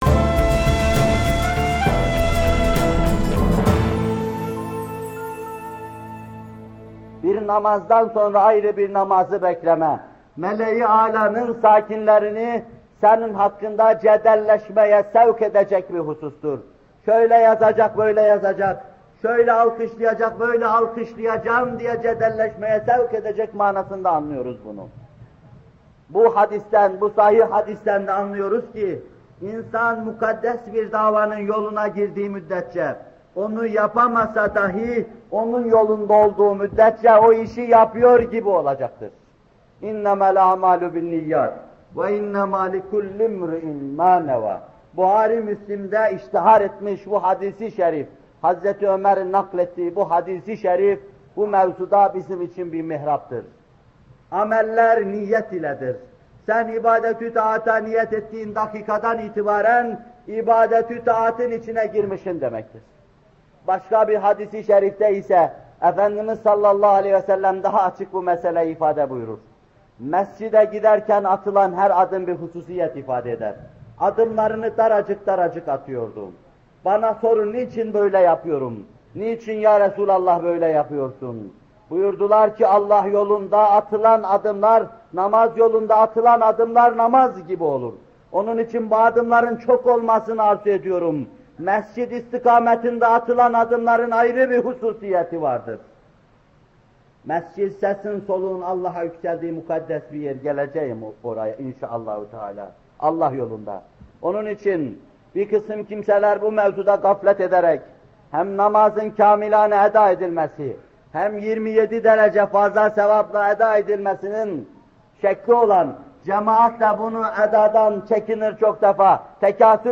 Bu bölüm Muhterem Fethullah Gülen Hocaefendi’nin 8 Eylül 1978 tarihinde Bornova/İZMİR’de vermiş olduğu “Namaz Vaazları 4” isimli vaazından alınmıştır.